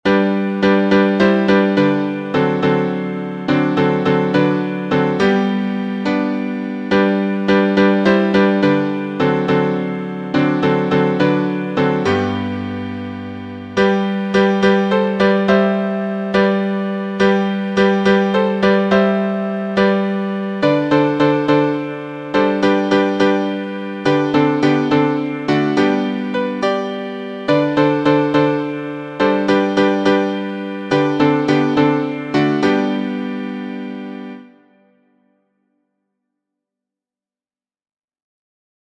public domain hymns